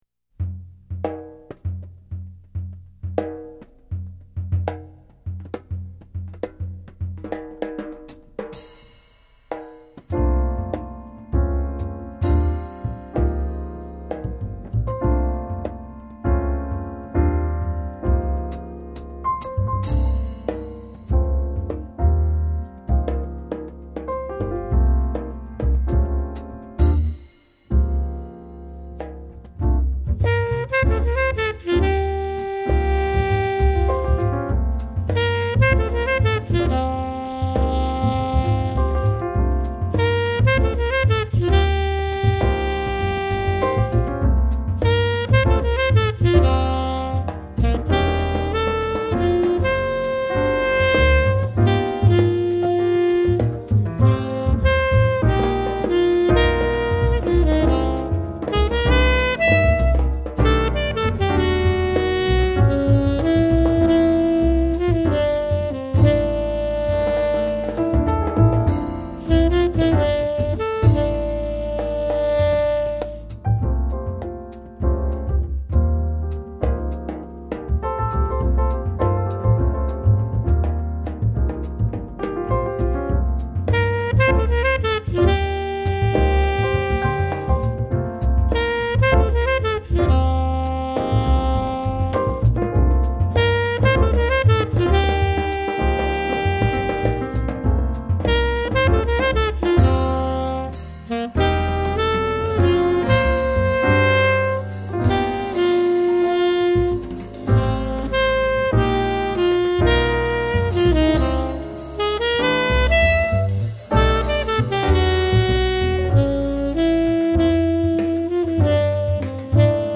Jazz
Tenor saxophonist
piano
bass
softly melodic